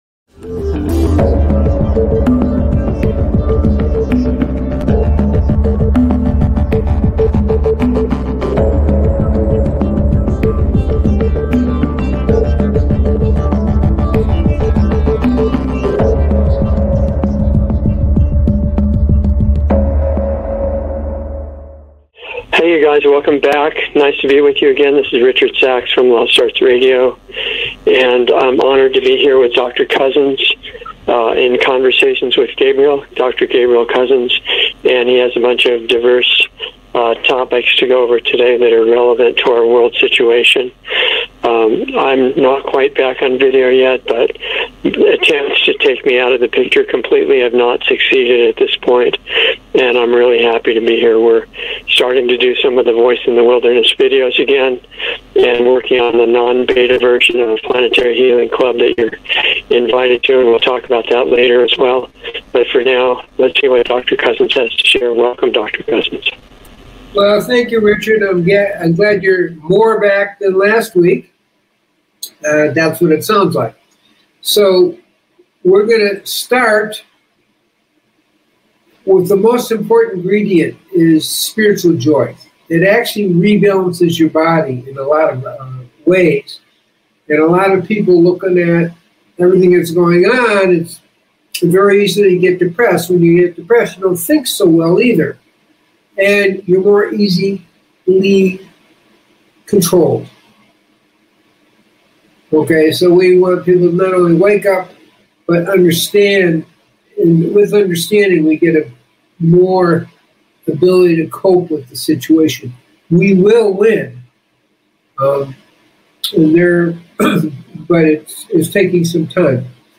A new LIVE series